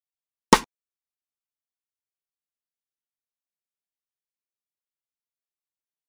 スネア音
スネアも残響音がすくなめなタイトな音が向いているかもしれませんね。
クラップと同じくHALION SONIC 7のHip Hop Kit 5にあるC#1の音です。